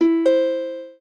lyre_ec1.ogg